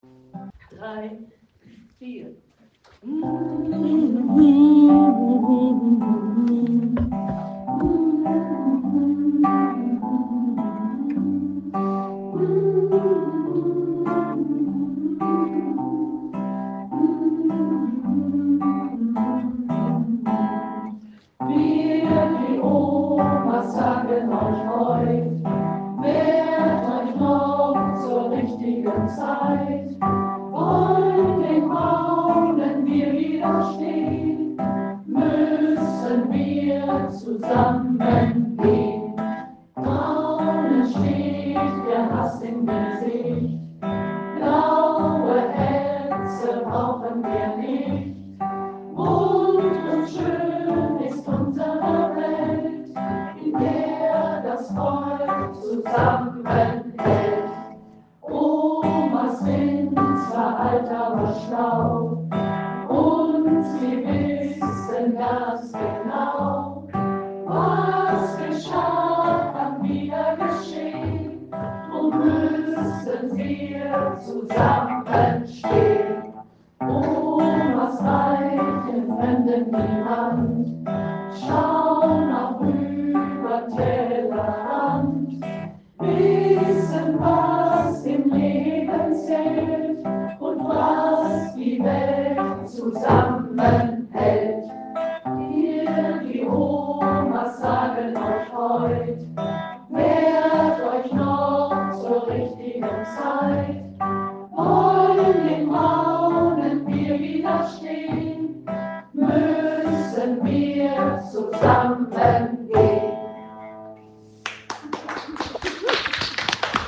Wer nicht da war, hat was verpasst 🙂 Was für eine tolle erste Probe des “OMA Chors” – das erste Mal zusammen gesungen und es passte sofort! Dank Geige, Gitarre und Percussion hatte es teilweise fast “Klezmer-Musik-Stil” 🙂 Leider haben wir es verpasst, von den schönen instrumentalen Teilen eine Aufnahme zu machen, aber wenigstens einen ersten Titel haben wir “mitgeschnitten”. Dafür, dass es unsere allererste Probe war, ist es richtig gut geworden – und nächstes Mal wird es noch etwas “schneller” 🙂
ogrgt-chor-erste-aufnahme.mp3